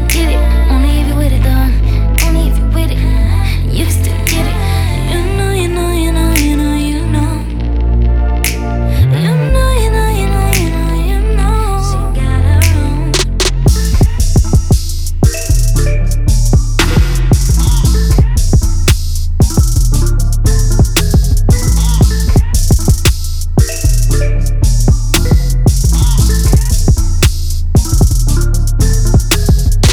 Genre: Soundtrack